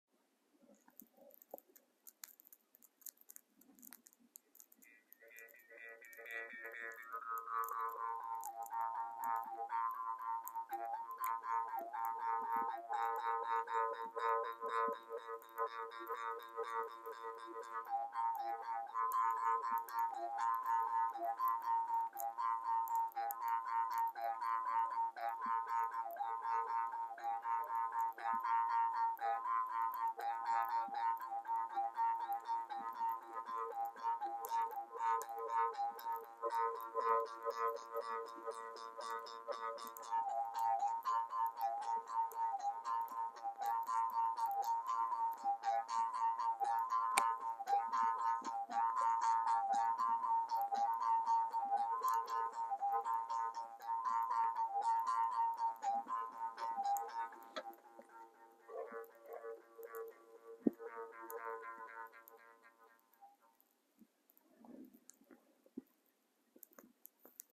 Jews Harp Song